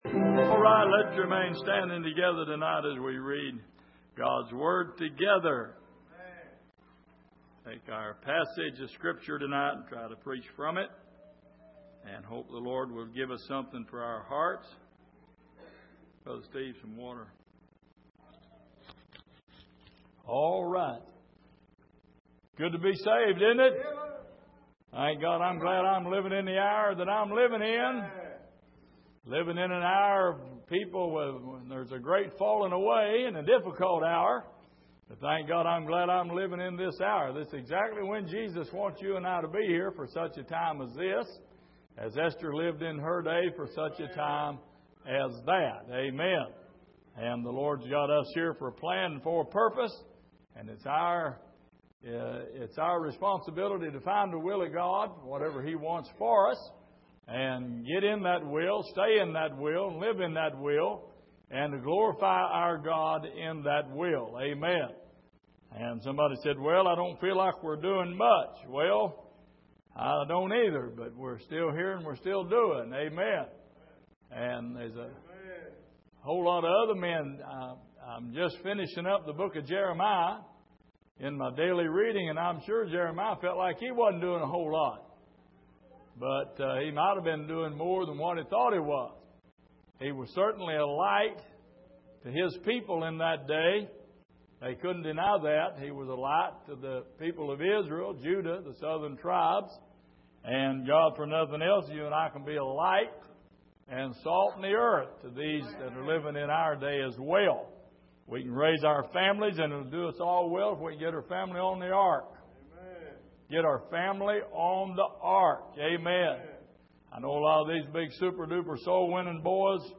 Passage: Psalm 78:1-20 Service: Sunday Evening